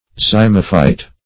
Search Result for " zymophyte" : The Collaborative International Dictionary of English v.0.48: Zymophyte \Zym"o*phyte\, n. [Zyme + Gr. fyto`n a plant.]